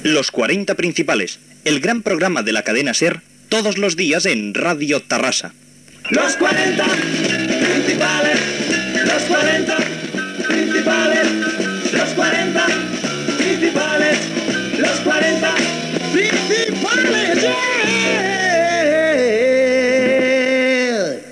Indicatiu del programa
Musical